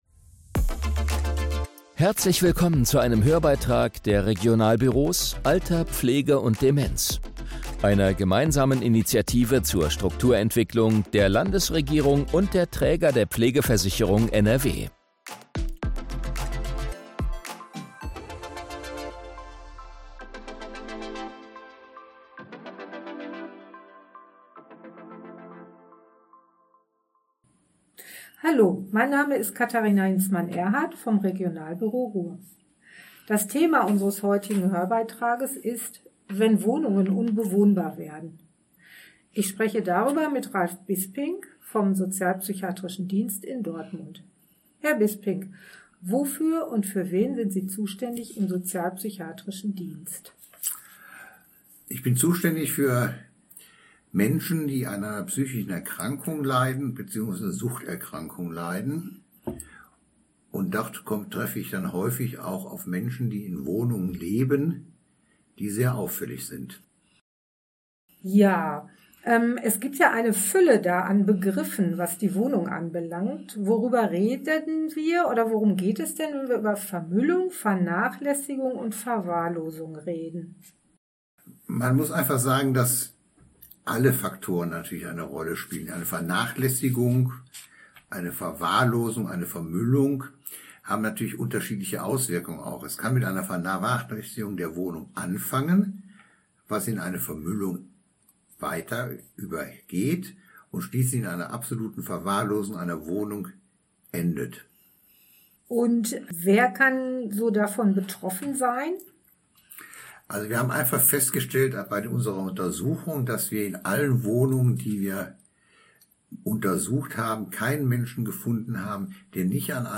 Hörbeitrag: Umgang mit Wohnungsverwahrlosung für Begleiter*innen – Informationen für Unterstützende